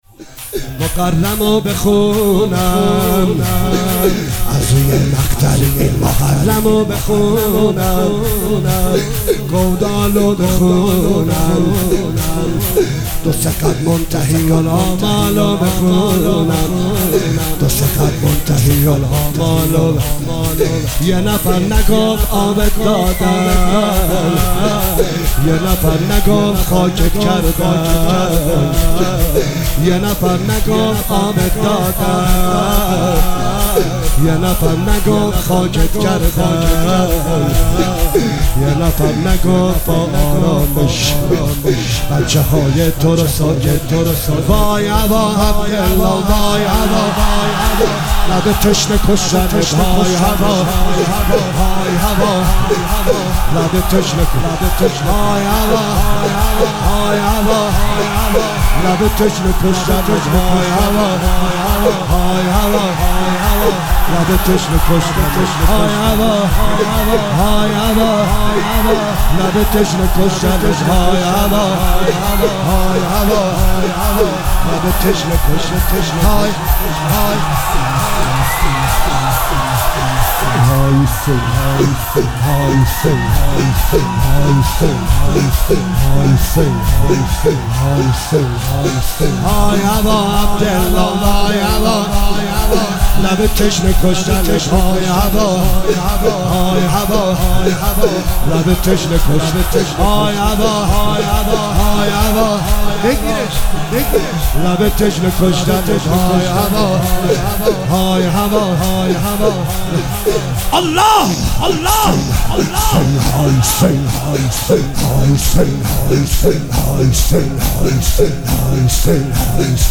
مداحی شور روز تاسوعا نهم محرم
روز تاسوعا 1404